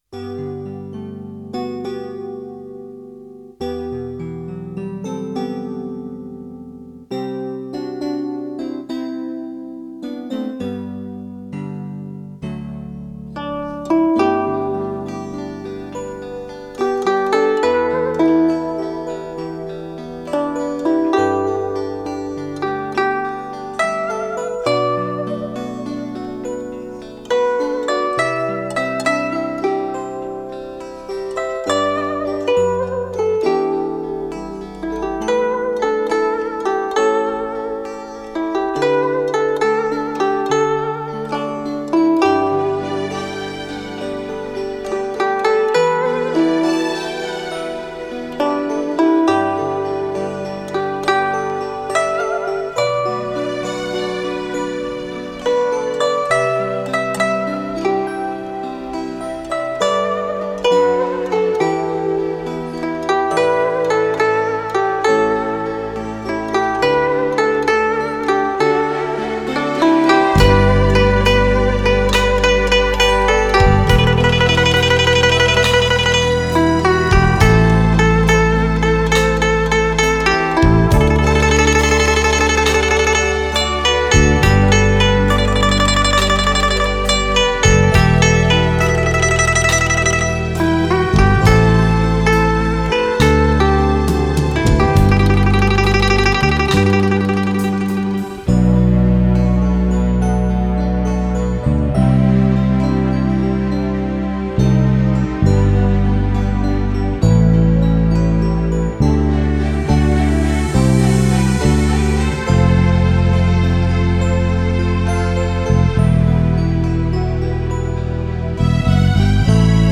再配合电子乐的迷离色彩，带给你全身心的享受。